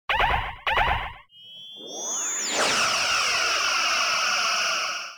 phaser.ogg